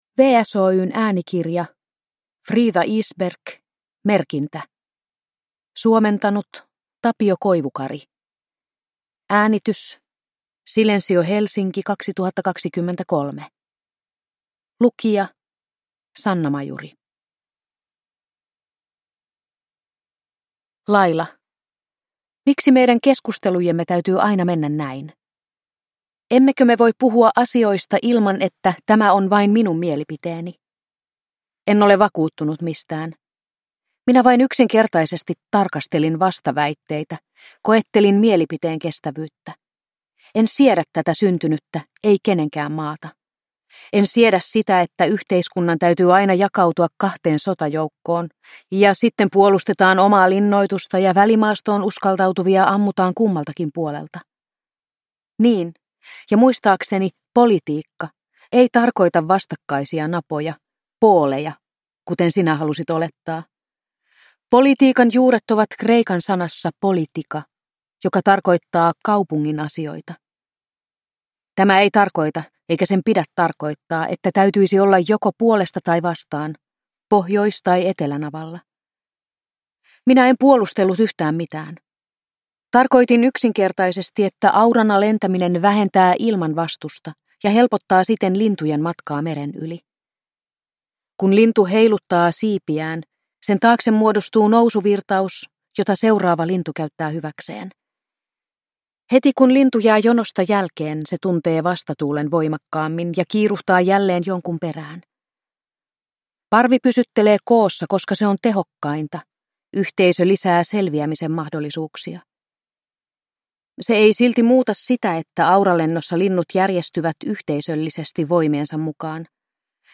Merkintä – Ljudbok – Laddas ner